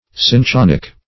Cin*chon"ic